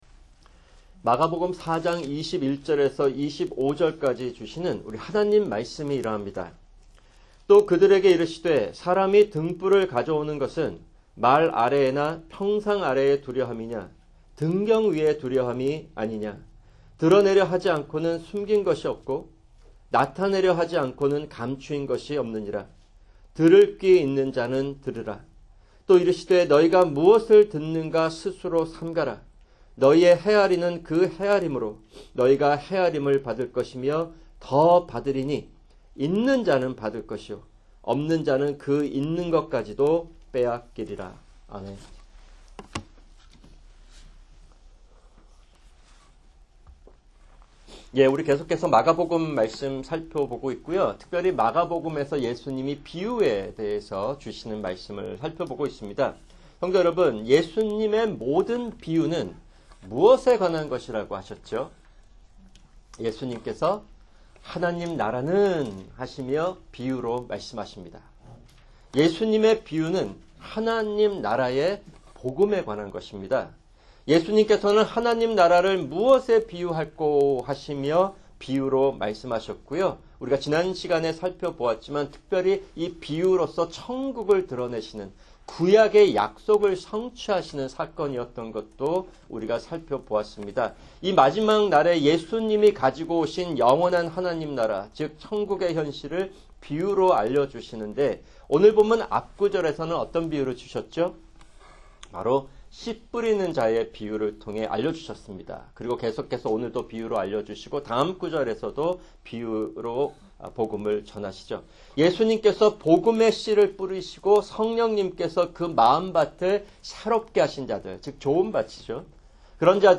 [주일 설교] 마가복음(20) 4:21-25